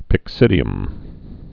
(pĭk-sĭdē-əm)